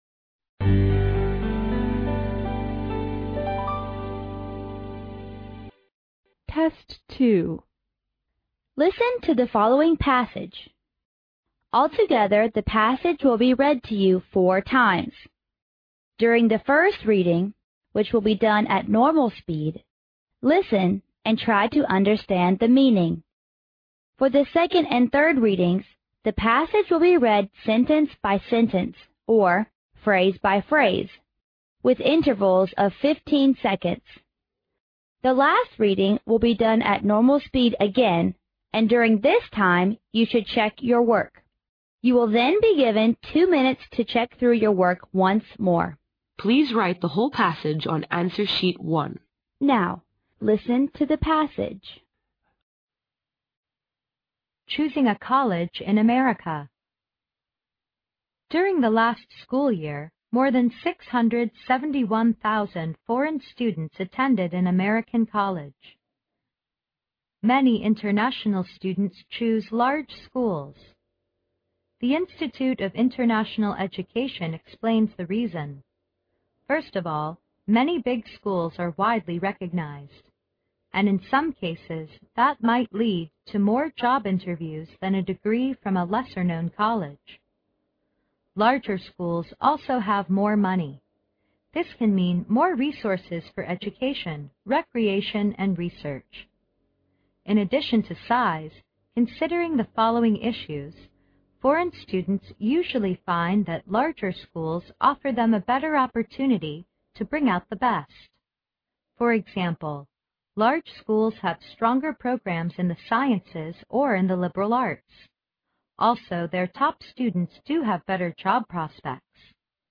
Listen to the following passage. Altogether the passage will be read to you four times.
The last reading will be done at normal speed again and during this time you should check your work.